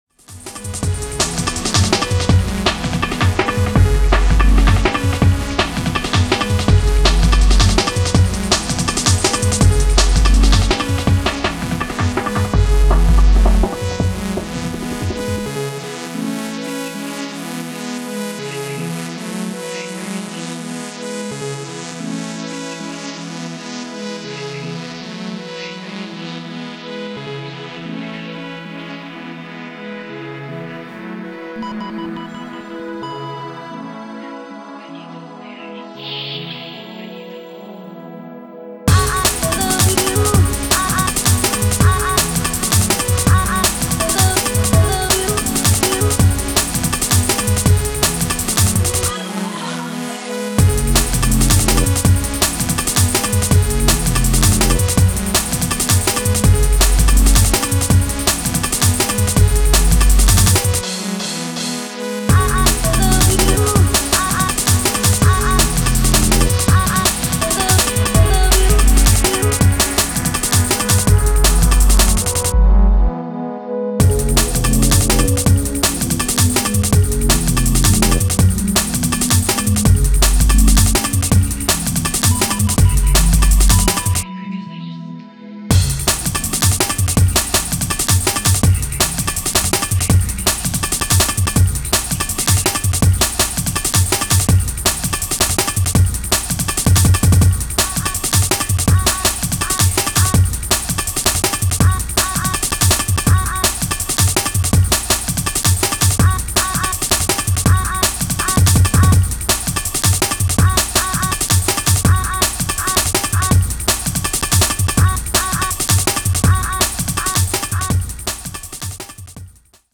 滑空するアパッチブレイクと呑気なシンセの組み合わせが妙な魅力のジャングル